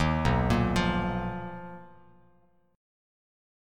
BM#11 chord